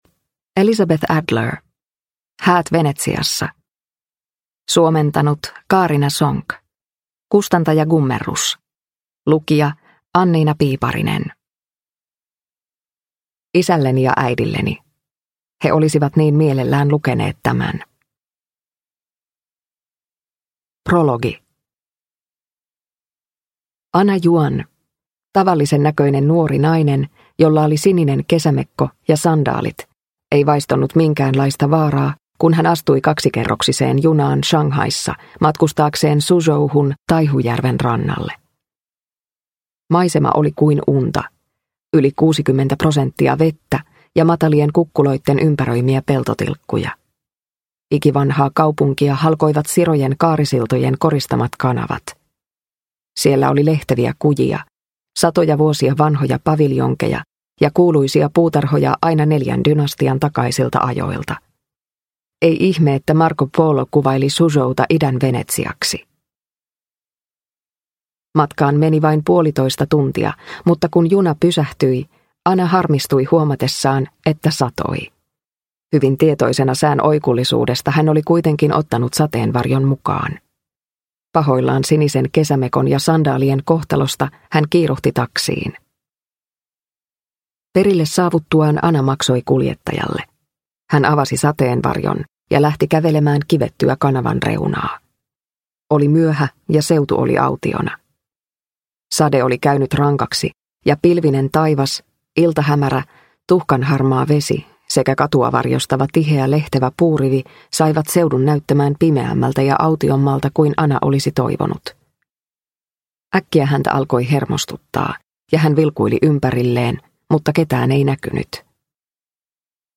Häät Venetsiassa – Ljudbok – Laddas ner